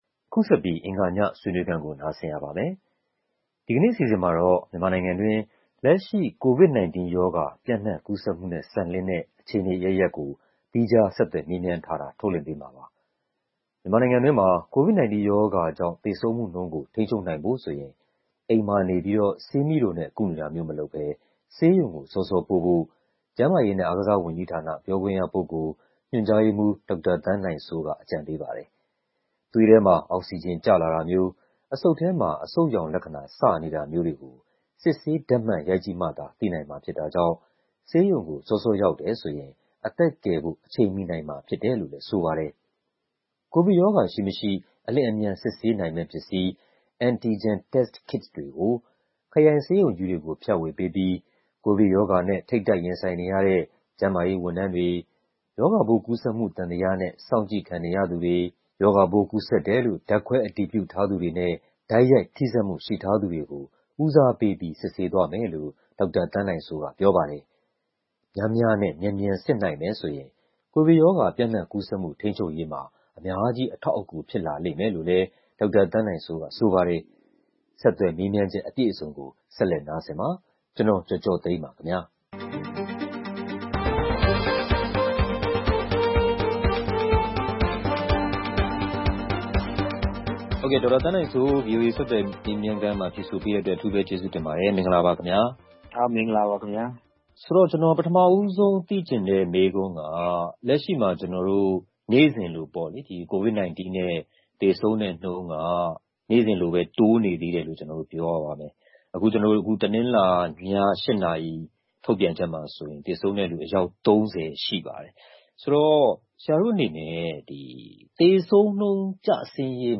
ကိုဗစ်ကြောင့်သေဆုံးသူ လျော့ကျရေးနဲ့ အခြေအနေအရပ်ရပ် (အင်္ဂါည ဆွေးနွေးခန်း)